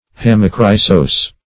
Search Result for " hammochrysos" : The Collaborative International Dictionary of English v.0.48: Hammochrysos \Ham`mo*chry"sos\ (h[a^]m`m[-o]*kr[imac]"s[o^]s), n. [L., fr. Gr.